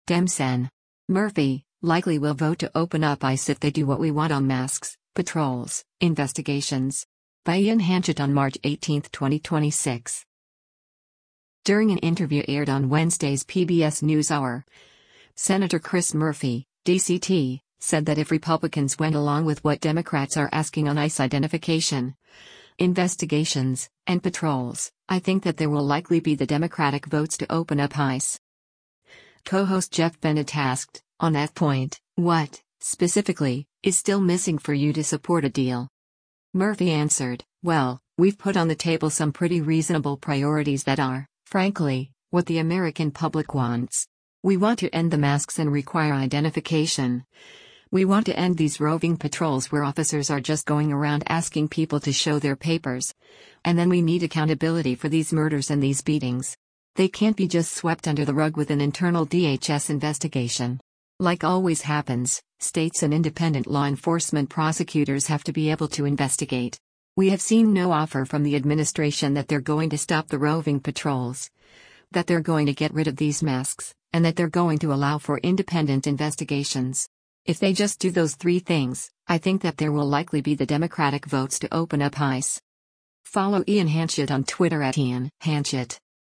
During an interview aired on Wednesday’s “PBS NewsHour,” Sen. Chris Murphy (D-CT) said that if Republicans went along with what Democrats are asking on ICE identification, investigations, and patrols, “I think that there will likely be the Democratic votes to open up ICE.”